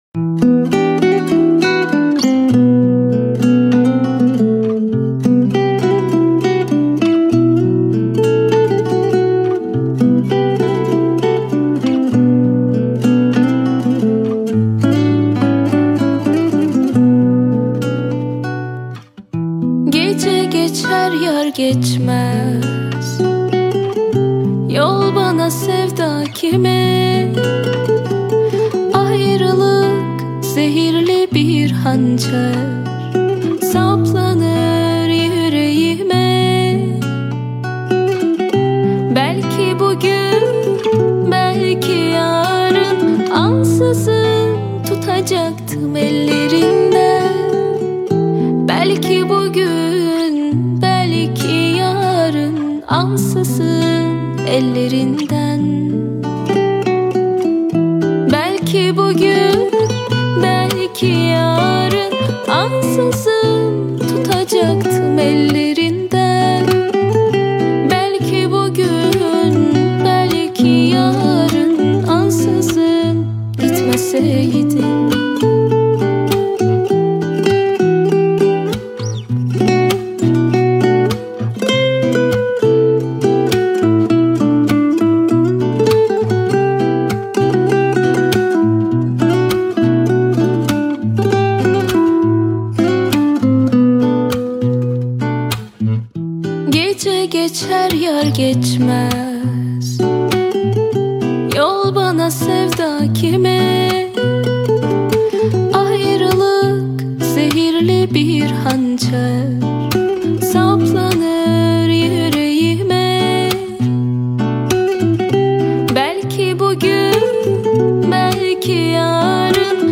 آهنگ ترکیه ای آهنگ غمگین ترکیه ای